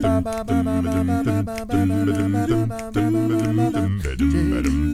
ACCAPELLA 1E.wav